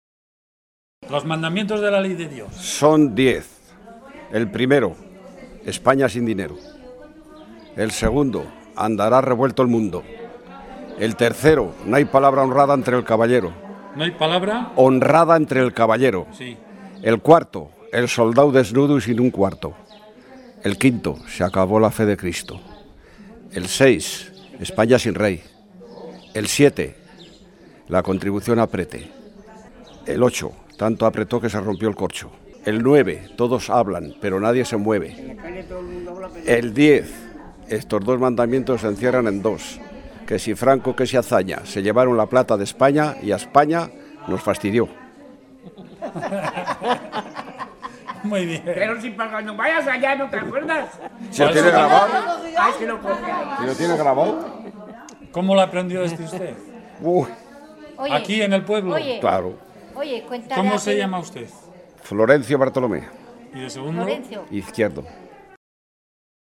Clasificación: Oraciones burlescas
Lugar y fecha de recogida: Santa Coloma, 30 de julio de 2004